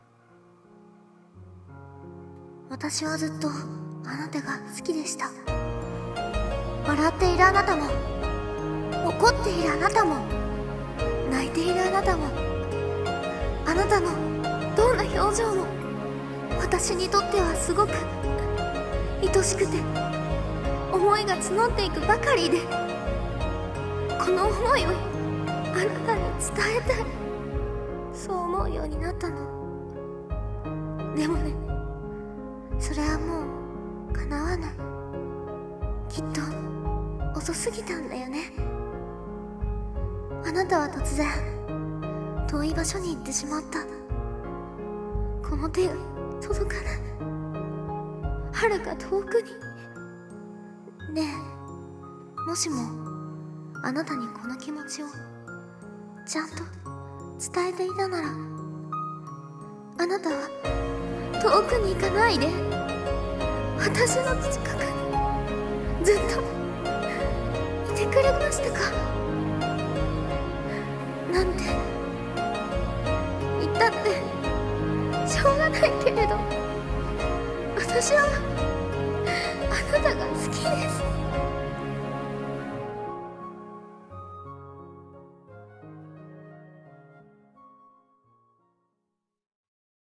朗読.セリフ【遅すぎた言葉】声劇